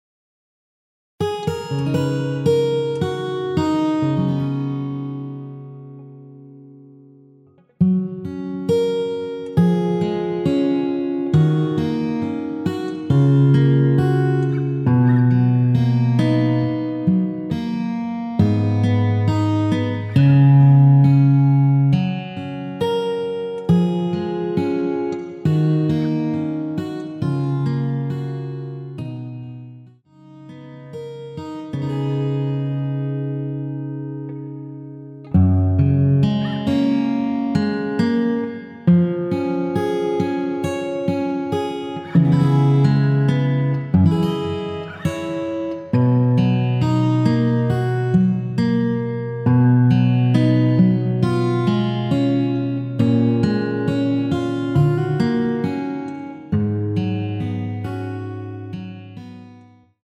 원키에서(+2)올린 MR입니다.
F#
앞부분30초, 뒷부분30초씩 편집해서 올려 드리고 있습니다.
축가 MR